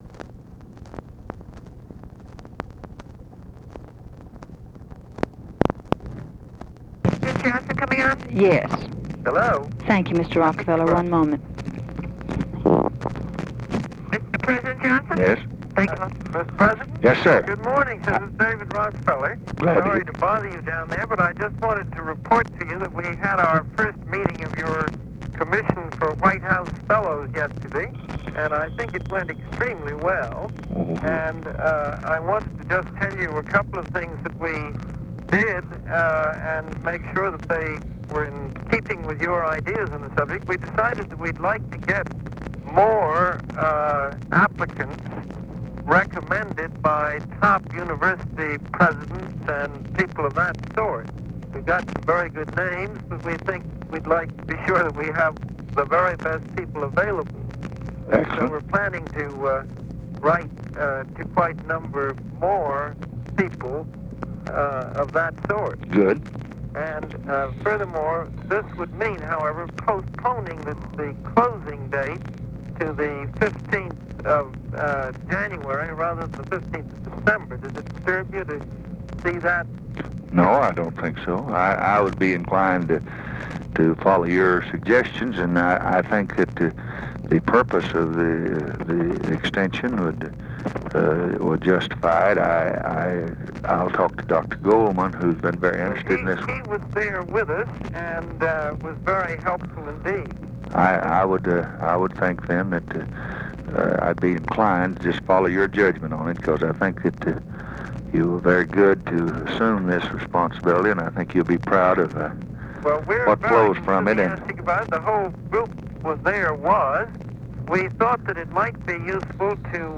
Conversation with DAVID ROCKEFELLER, November 11, 1964
Secret White House Tapes